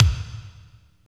28.09 KICK.wav